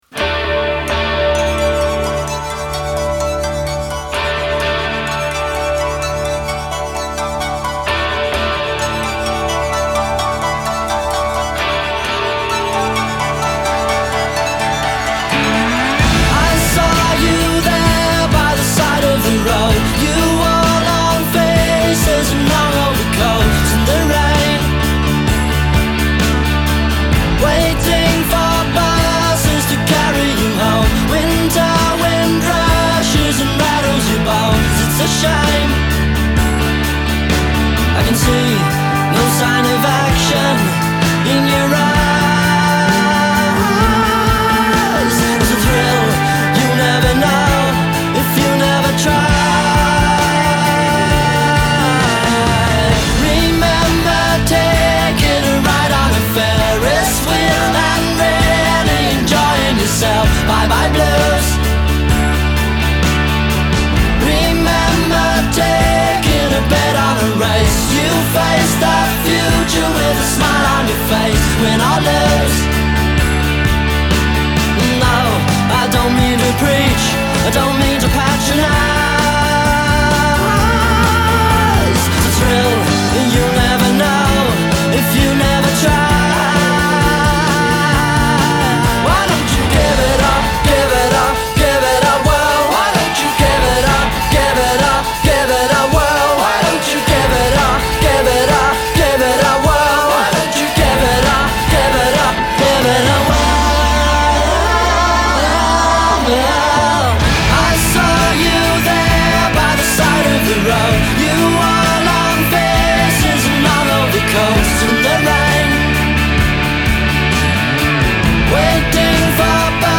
Genre: Hip Hop, Jazz, Rock, Funk